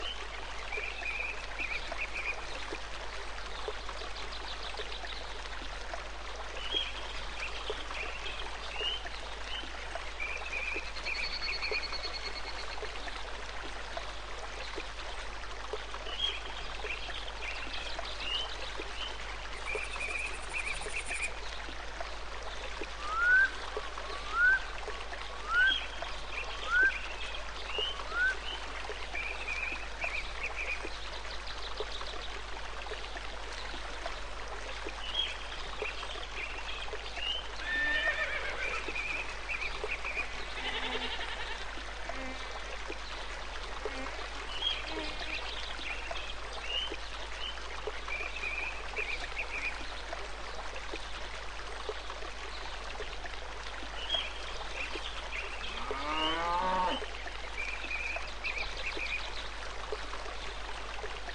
Babbling Brook (1 Hour)
Nature sounds are recorded & designed to help people sleep, allowing you to relax and enjoy the sounds of nature while you rest or focus, with no adverts or interruptions.
Perfect for their masking effects, they are also helpful for people suffering with tinnitus.
Babbling-Brook-Sample.mp3